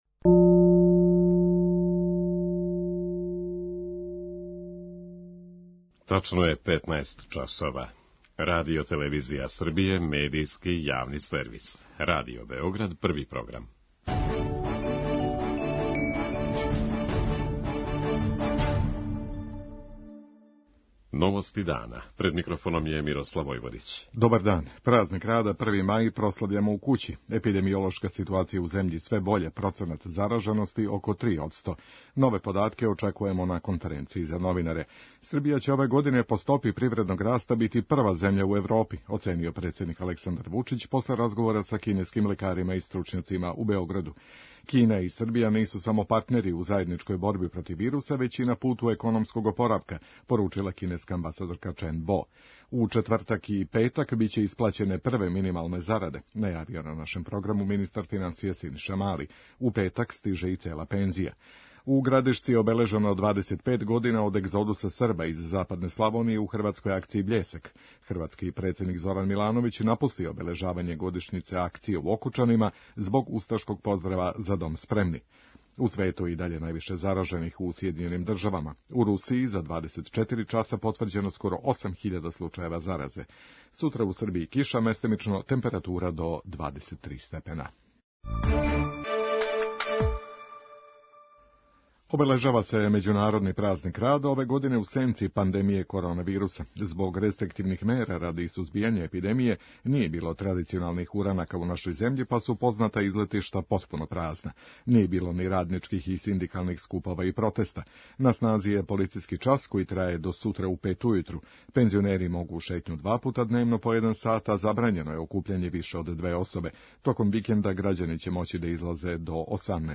Najnovije podatke o epidemiološkoj situaciji u zemlji čućete u okviru emisije, u prenosu konferencije za novinare članova Kriznog štaba.